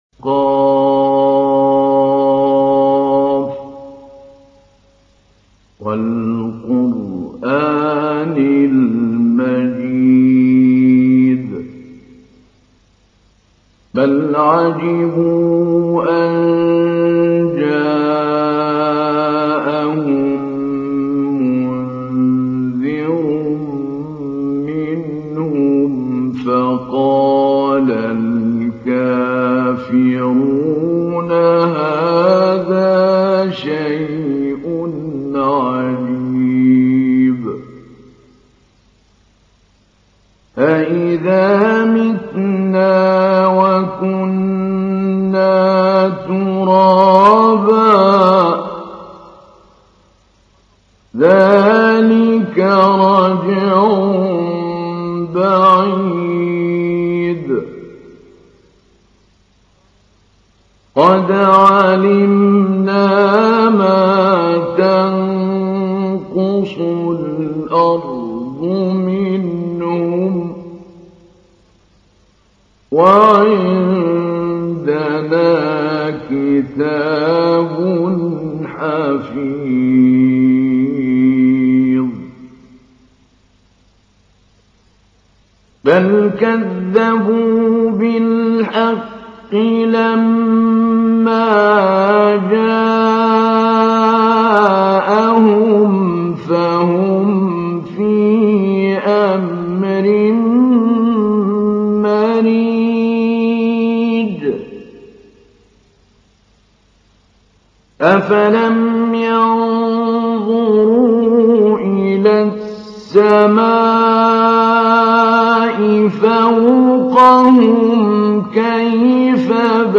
تحميل : 50. سورة ق / القارئ محمود علي البنا / القرآن الكريم / موقع يا حسين